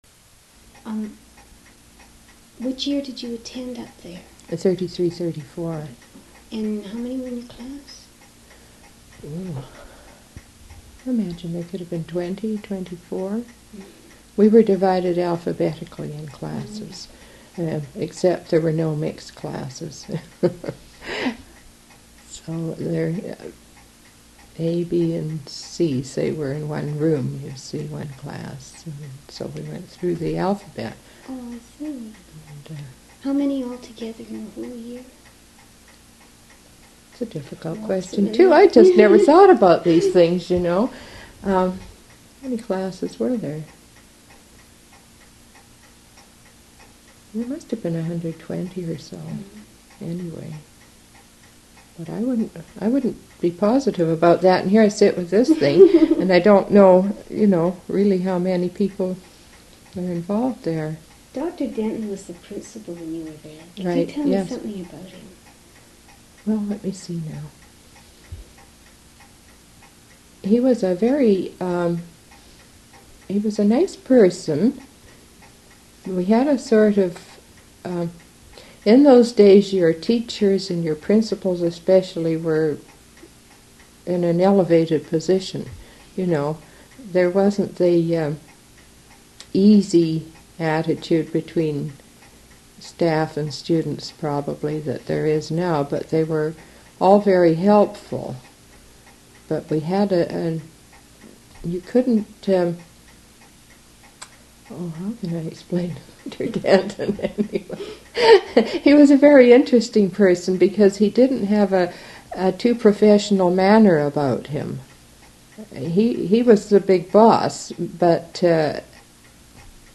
oral histories
Audio cassette copied in 1992.